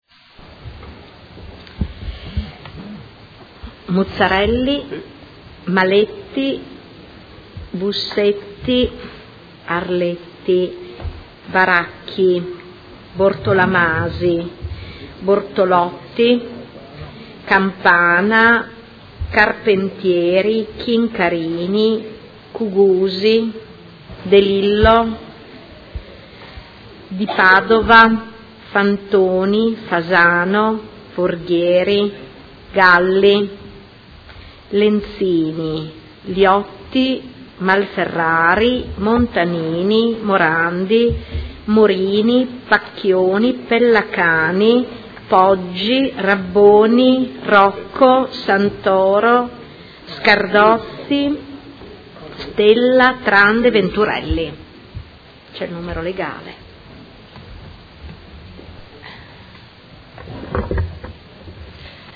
Seduta del 20/10/2016. Appello